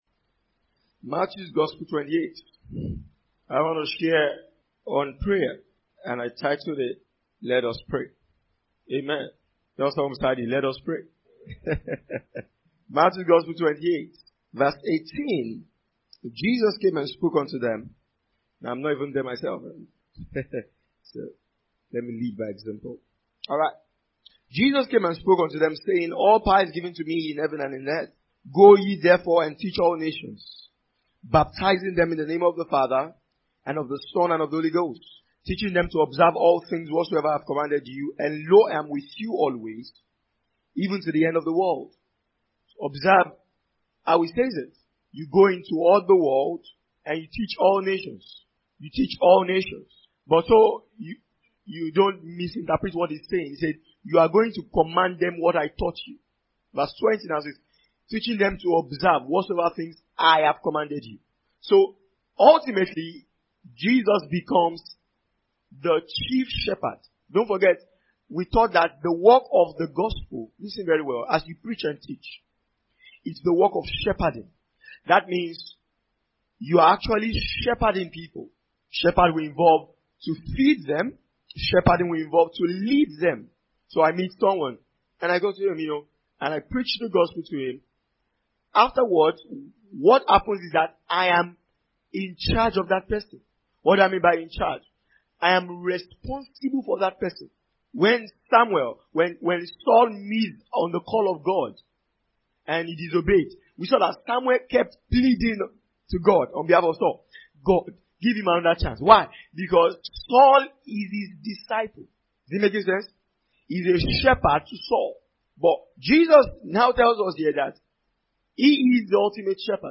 A two-track teaching that will spur the believer on to pray. It addresses the vitality of prayer and what should primarily be the content of a believer’s prayer. It shows us, as disciples of the life and teachings of Christ, how Jesus prayed and what he prayed for – which ought to be an example for us too.